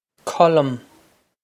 Colm Kuhl-um
This is an approximate phonetic pronunciation of the phrase.